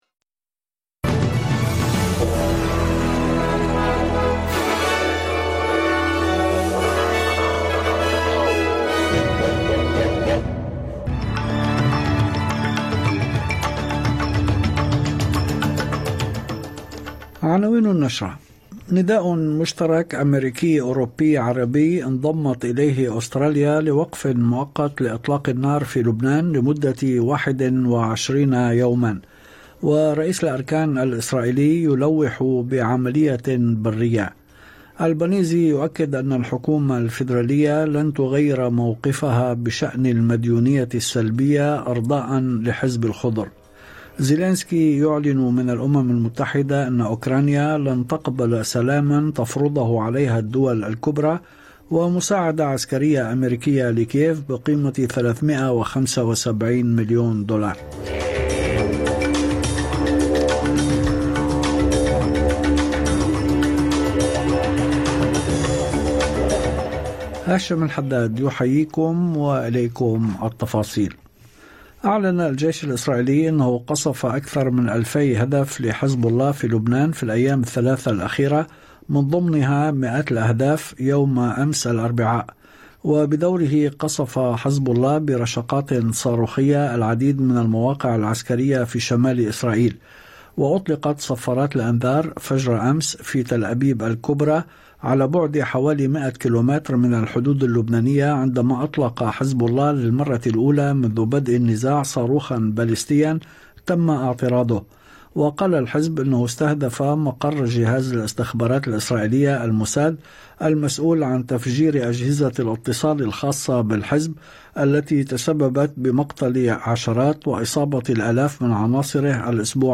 نشرة أخبار المساء 26/9/2024